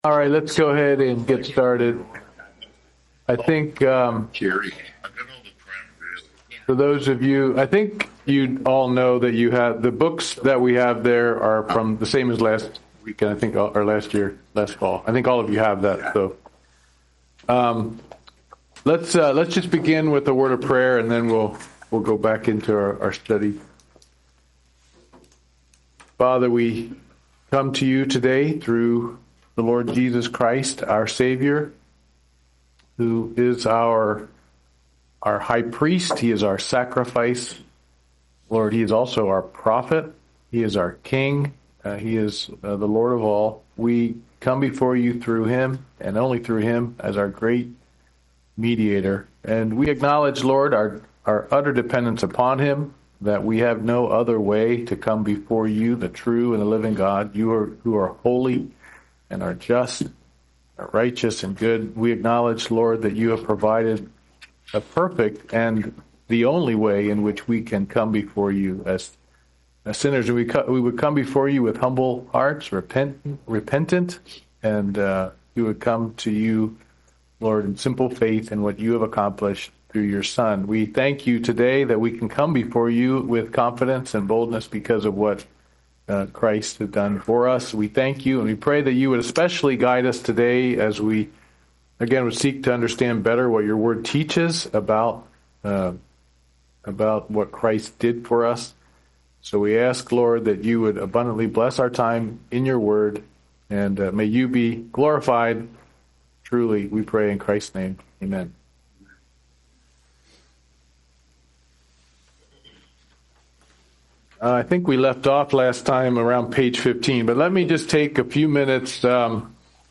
Service Type: Men's Bible Study